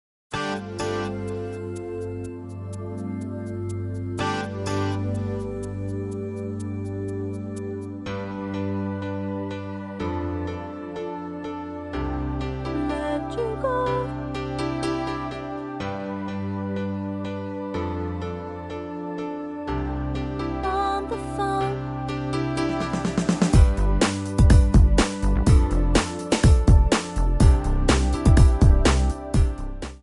Backing track files: All (9793)
Buy With Backing Vocals.